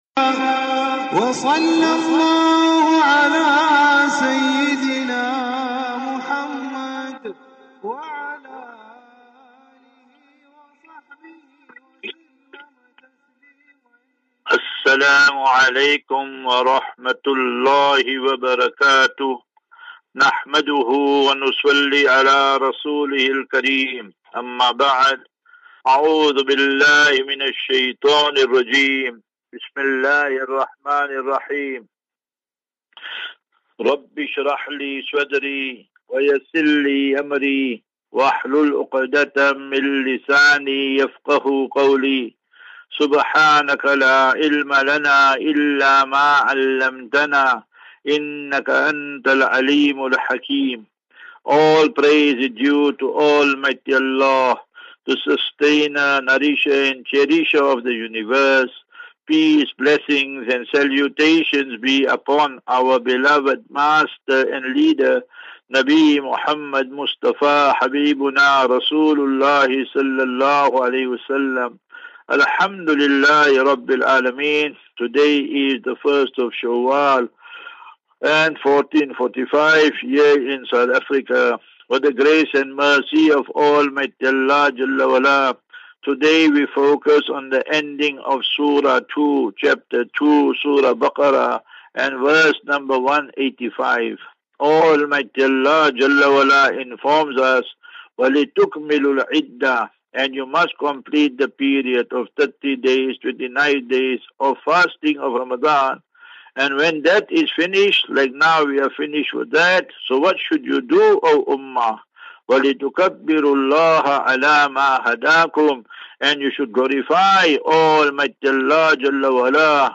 As Safinatu Ilal Jannah Naseeha and Q and A 11 Apr 11 April 2024.